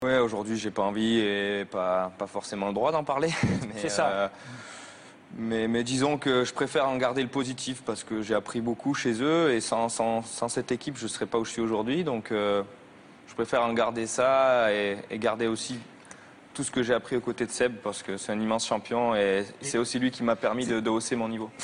Un petite voix de sportif... de qui?
un peu agacé .. Sebastien Ogier